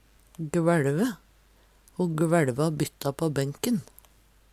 DIALEKTORD PÅ NORMERT NORSK gvæLve kvelve Infinitiv Presens Preteritum Perfektum gvæLve gvæLver gvæLte gvæLt Eksempel på bruk Ho gvæLte bytta på benken.